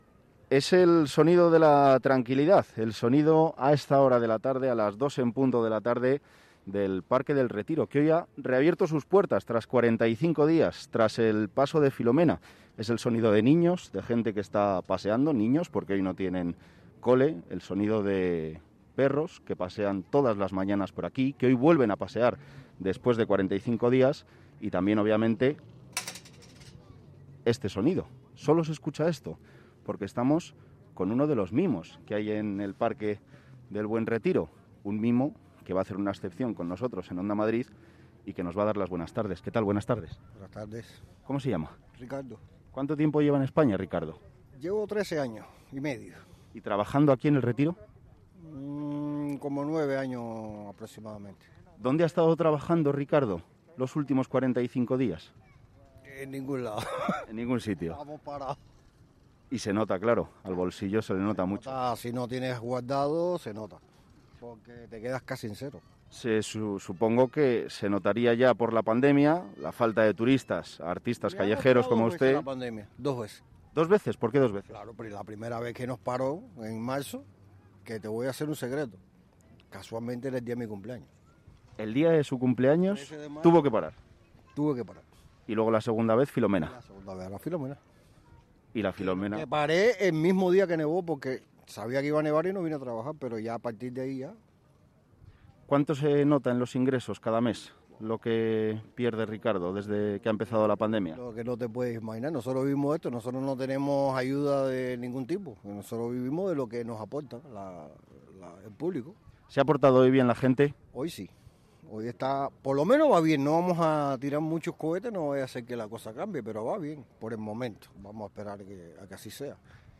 Es el sonido del parque del Retiro a esta hora.